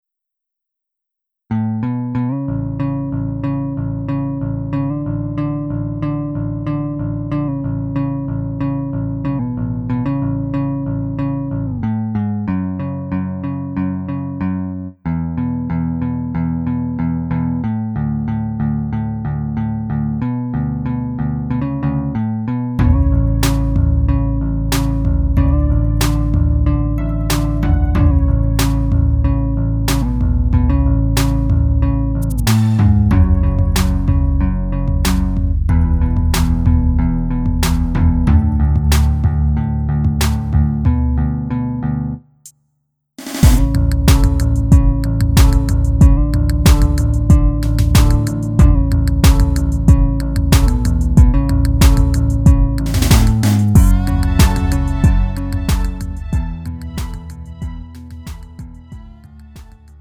음정 원키 3:26
장르 pop 구분 Lite MR